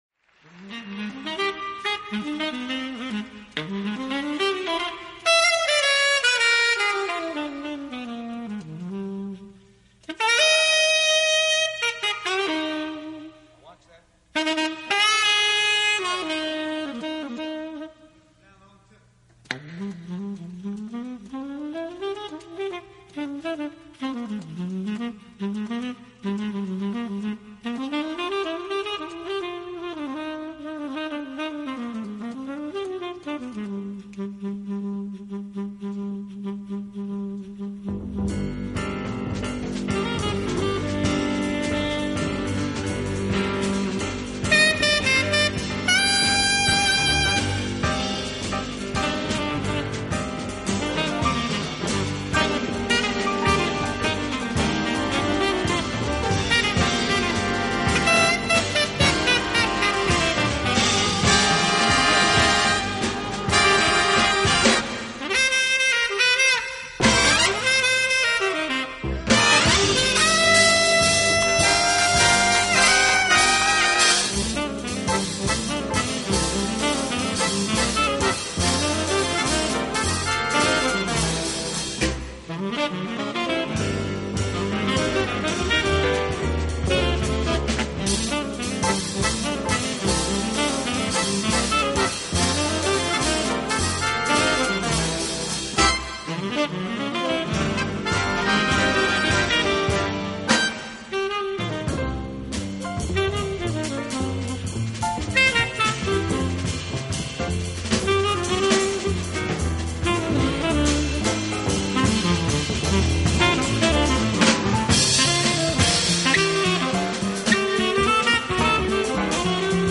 Genre：Jazz